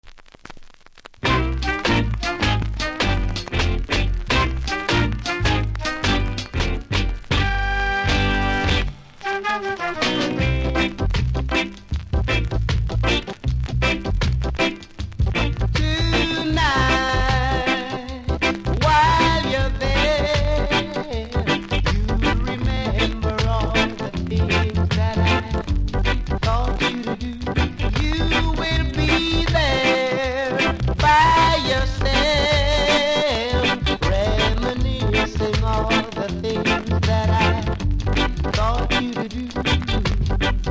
両面使えるロック・ステディ♪